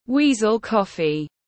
Cà phê chồn tiếng anh gọi là weasel coffee, phiên âm tiếng anh đọc là /ˈwiː.zəl ˈkɒf.i/
Weasel coffee /ˈwiː.zəl ˈkɒf.i/